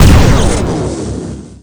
vlaunch_emp_hit.wav